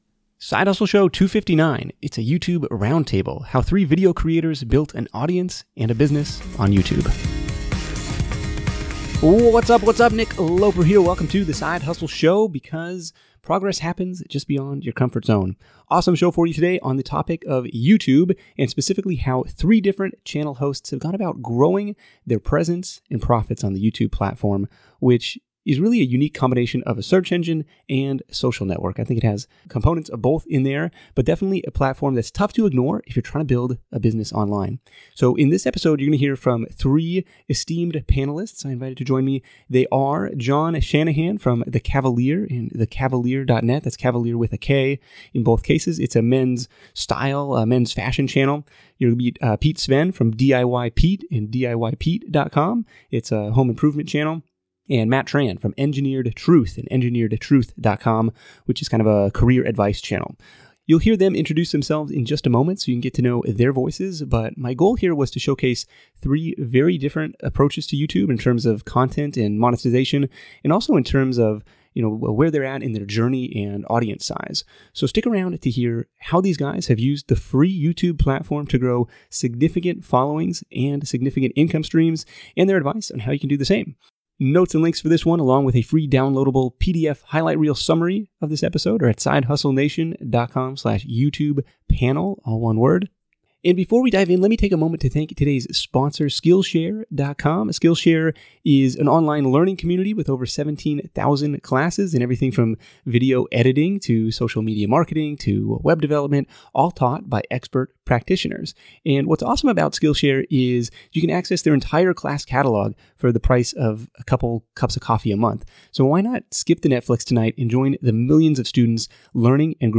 This week’s show is a roundtable episode on the topic of YouTube, and specifically how 3 different channel hosts have gone about growing their presence and profits on the YouTube platform.